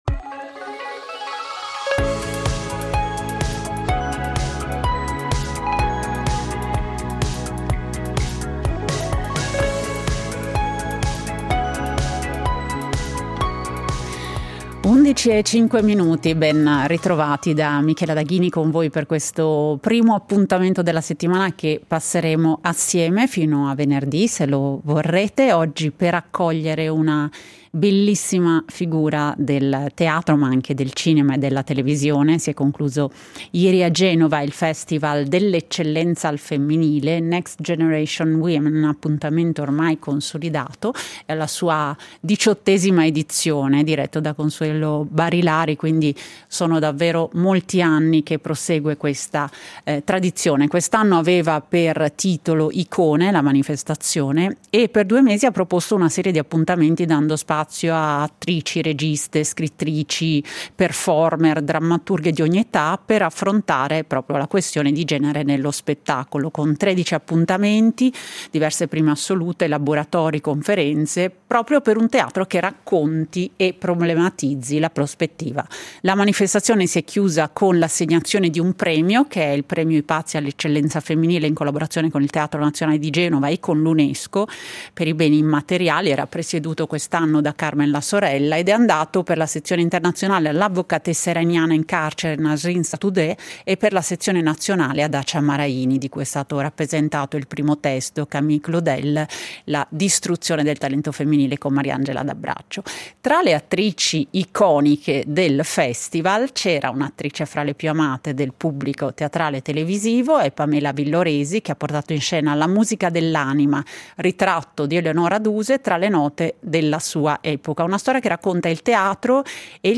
Talento e femminile. Incontro con Pamela Villoresi (3./3)